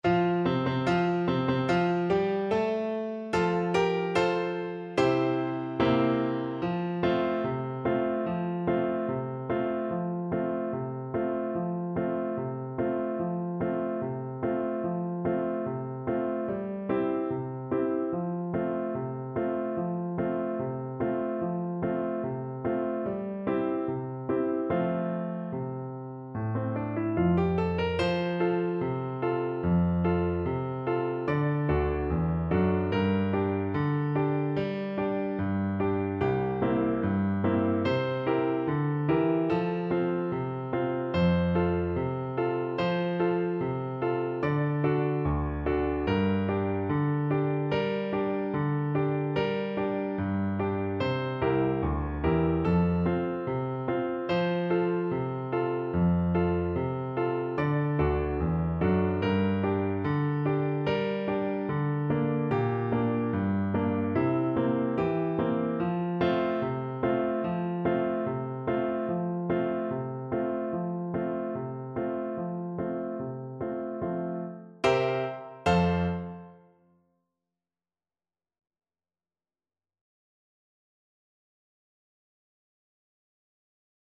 Flute
Traditional Music of unknown author.
F major (Sounding Pitch) (View more F major Music for Flute )
2/4 (View more 2/4 Music)
With energy =c.100
Mexican